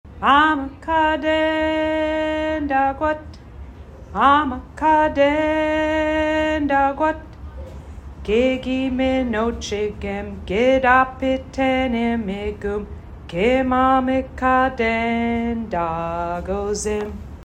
This song was created for all the students in Cook County who study Ojibwemowin. From Preschool to High School the song is sung in the community to celebrate student success.